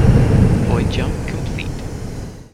The ship is already loud enough. Your voice is just too soft for the engine noise.
jump_complete.wav